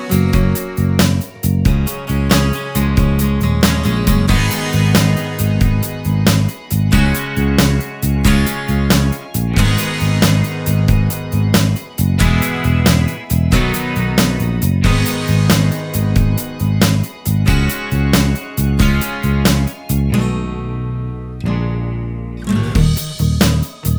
No Vocals At All Pop (1990s) 3:16 Buy £1.50